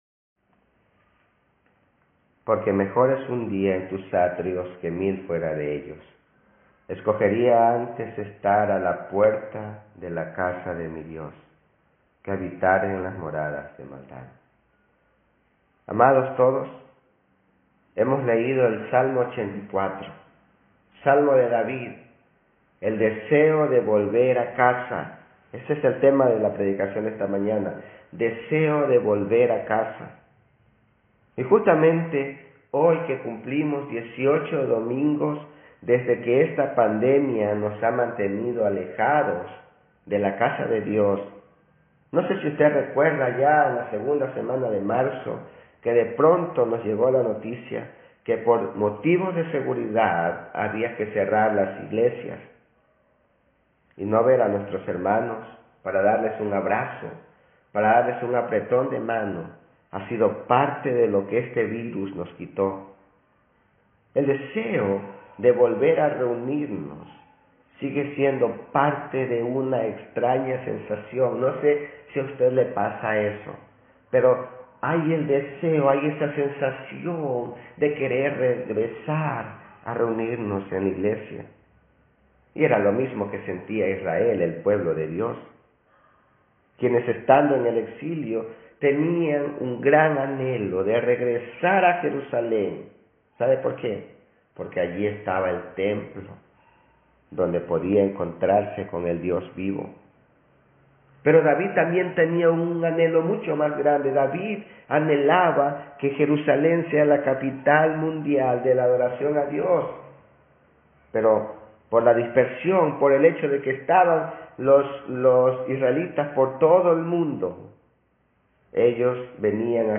Tipo: Sermón
predicación-sola.mp3